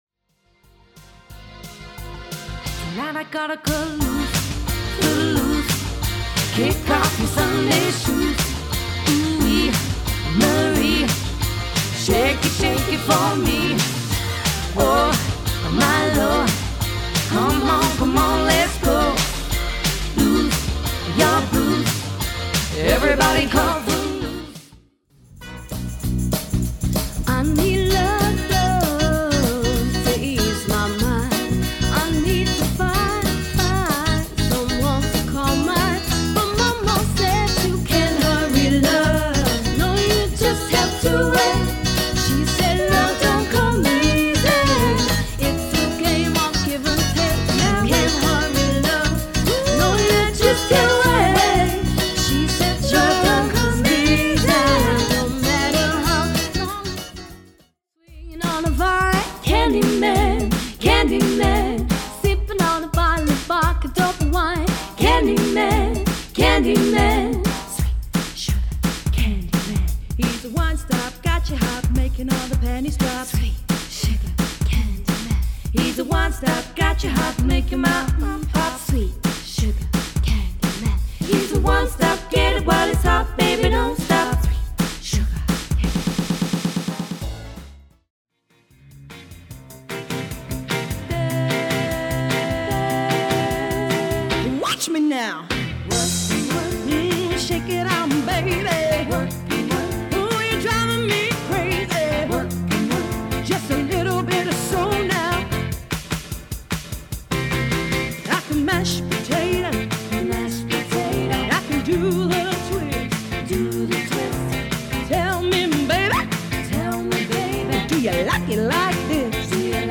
close harmony trio